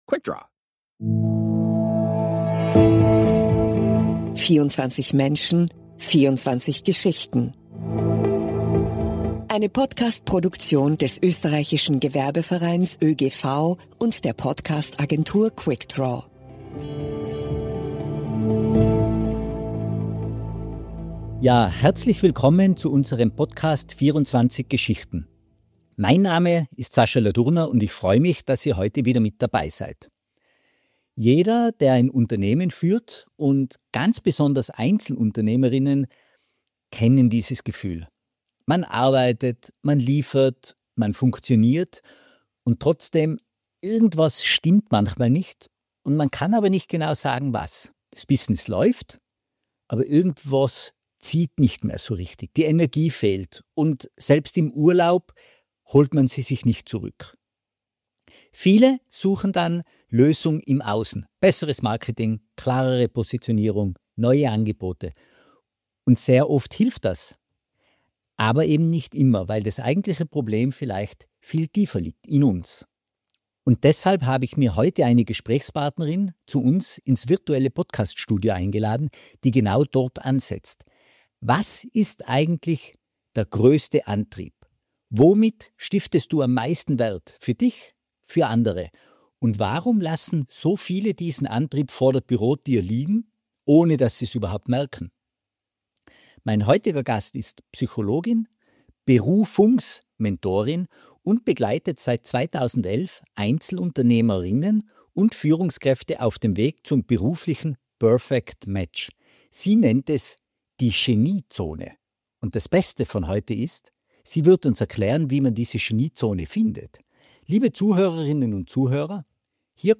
Im Gespräch geht es darum, wie EPU erkennen, ob sie am richtigen Platz sind, warum Positionierung ohne dieses Fundament nicht trägt – und was man tun kann, bevor der Schmerz zu groß ist, um sich zu bewegen.